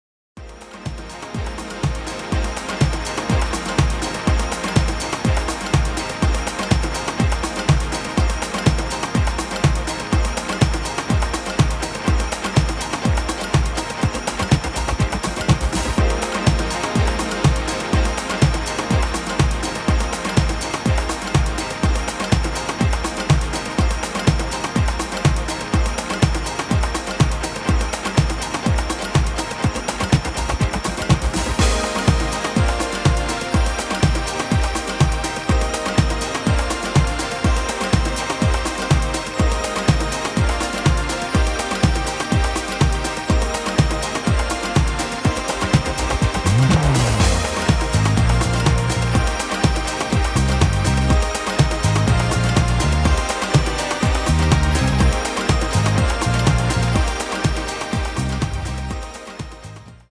多幸感溢れる煌めくようなシンセワークがクライマックスを彩るA-1